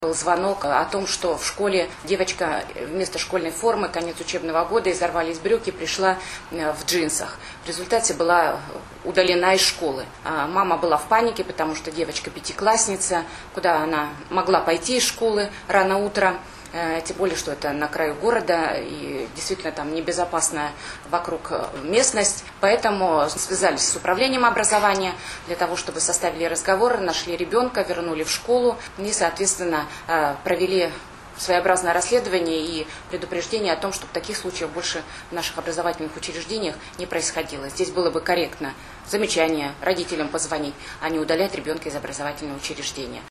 Об этом случае рассказала Уполномоченный по правам ребенка в Вологодской области Ольга Смирнова на пресс-конференции, посвященной Дню защиты детей.